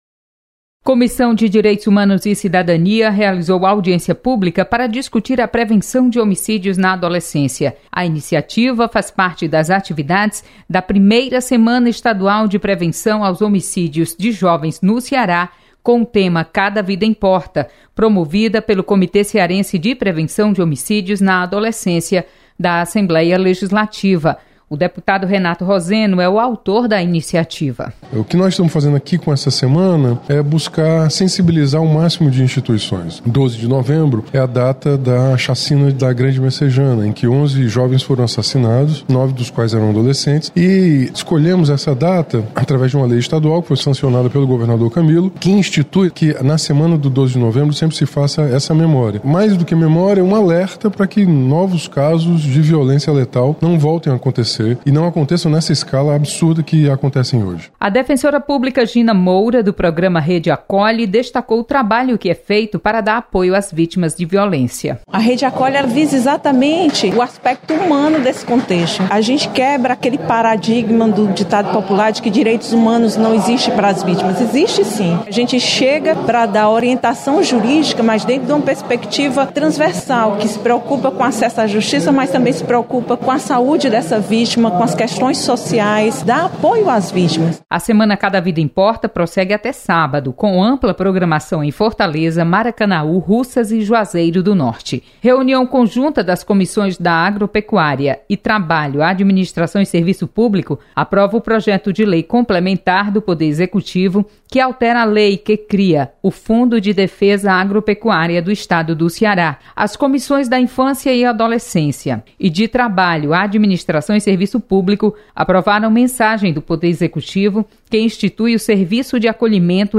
Acompanhe resumo das comissões técnicas permanentes da Assembleia Legislativa da Assembleia Legislativa. Repórter